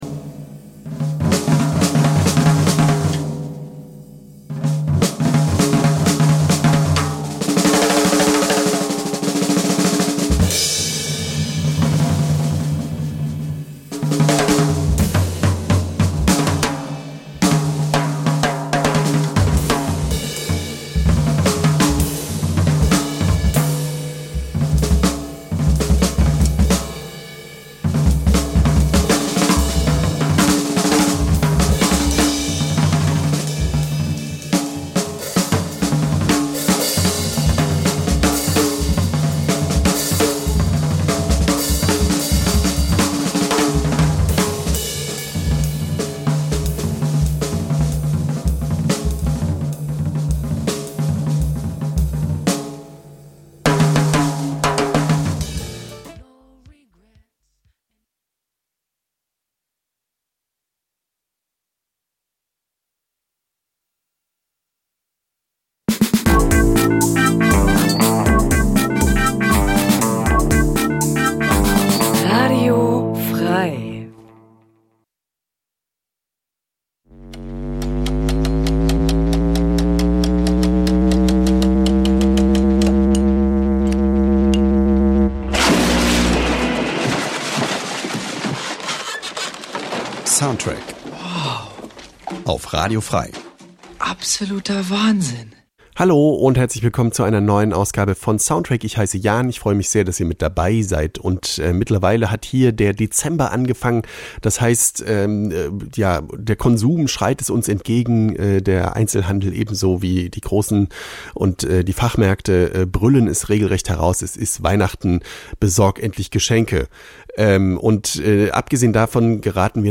Wöchentlich präsentieren wir ausgesuchte Filmmusik.